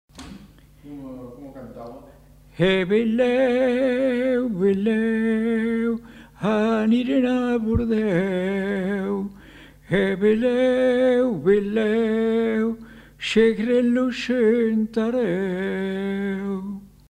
Aire culturelle : Périgord
Genre : forme brève
Type de voix : voix d'homme
Production du son : récité
Classification : formulette